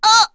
Erze-Vox_Damage_02.wav